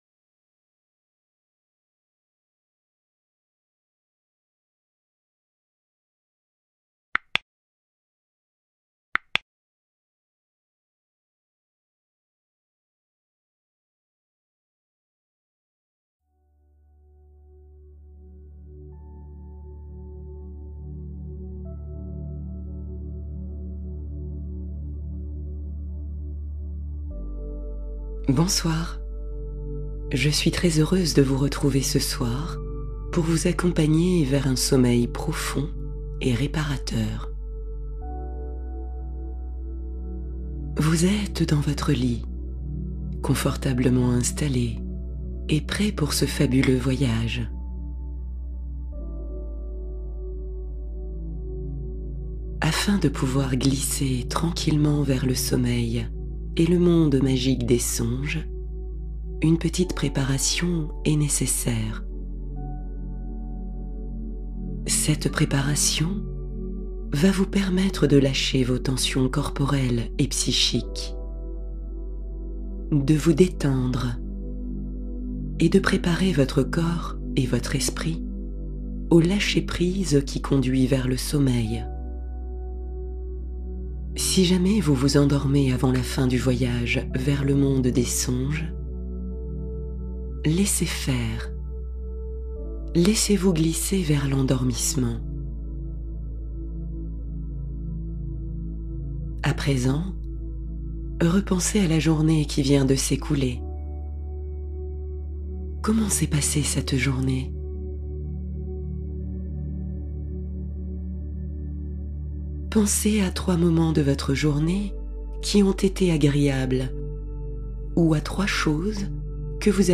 Le voyage de Lili : histoire douce pour accompagner l’endormissement